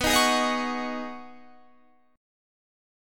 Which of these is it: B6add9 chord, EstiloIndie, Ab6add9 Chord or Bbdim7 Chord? B6add9 chord